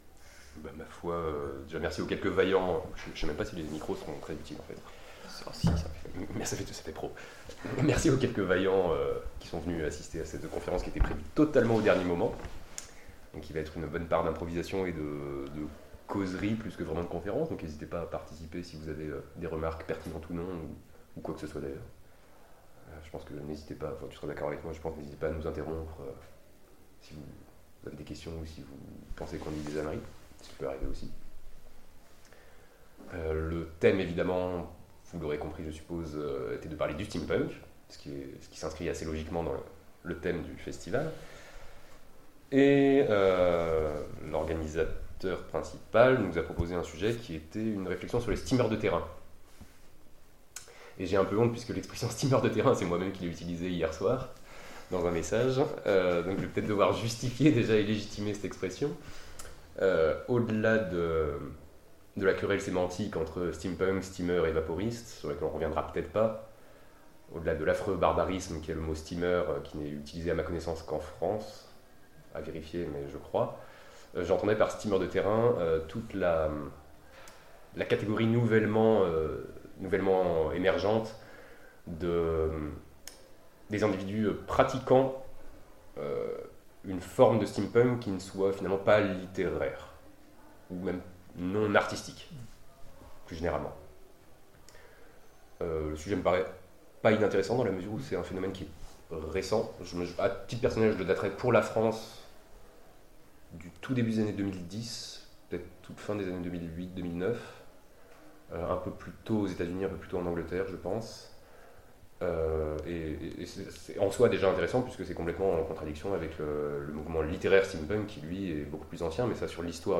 Intergalactiques 2015 : Conférence A propos des Steamers de terrain
Intergalactiques_2015_conference_A_propos_des_Steamers_de_terrain_ok.mp3